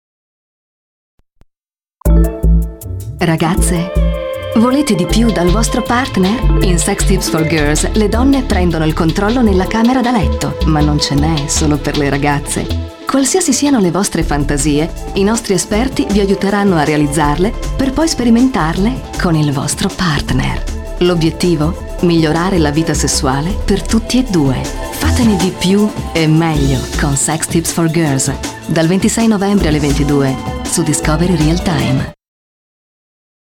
Pungente, graffiante, eclettica, frizzante, ironica ma con sentimento. Il tocco di zucchero nella salsa che stempera l'acidità, il pizzico di sale nella torta per dare grinta alla dolcezza.